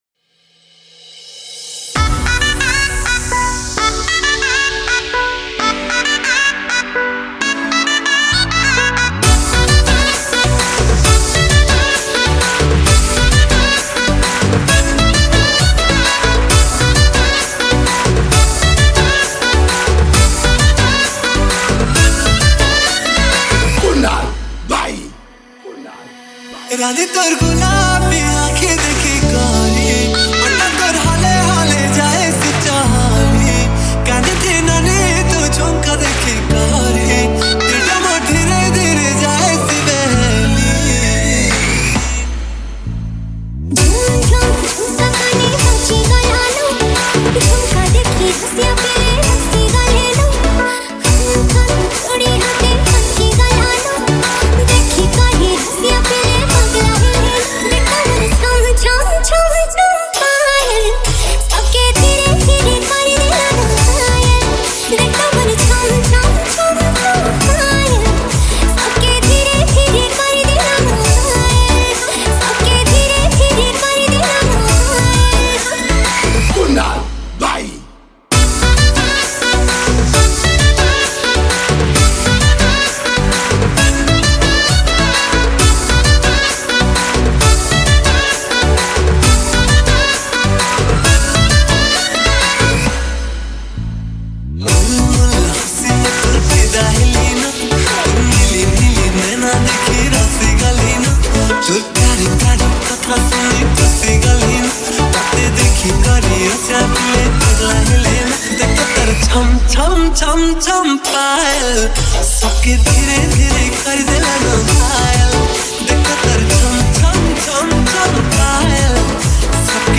Category:  Sambalpuri Dj Song 2024